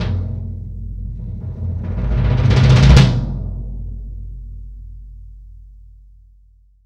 Index of /90_sSampleCDs/AKAI S6000 CD-ROM - Volume 3/Kick/GONG_BASS